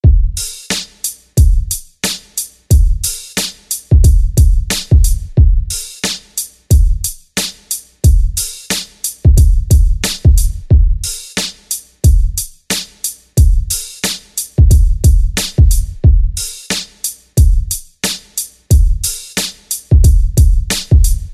描述：嘻哈鼓循环 90bpm